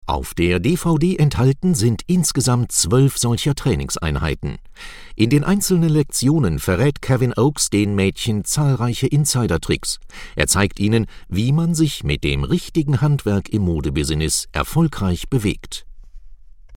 Sprecher deutsch.
Sprechprobe: eLearning (Muttersprache):
german voice over artist